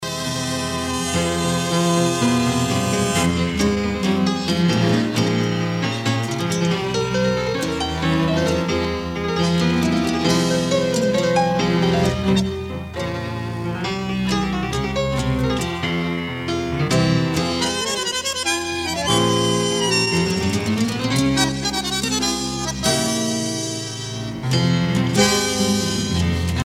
danse : tango (Argentine, Uruguay)